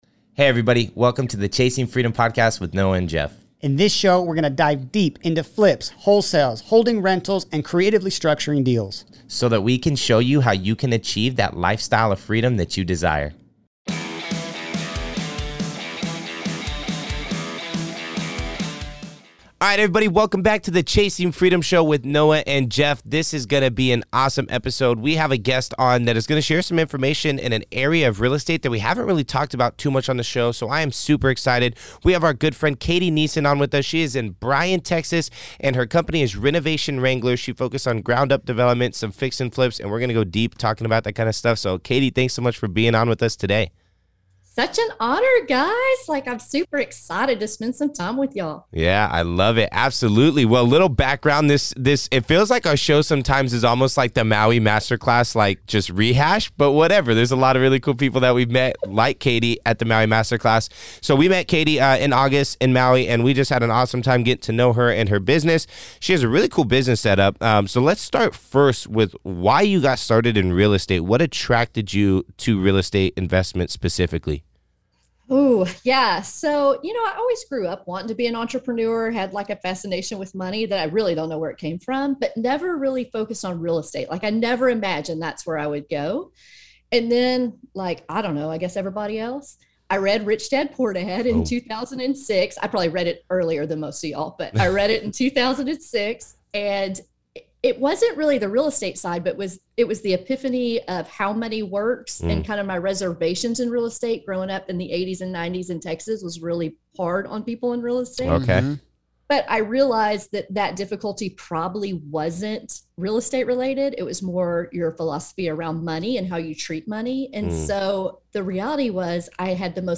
We dive deep into bankruptcy, the brrrr method, the advantages of having a business network, and the sacrifices required to get into the industry. By far one of our most fun interviews to date, and I think you guys will learn a lot!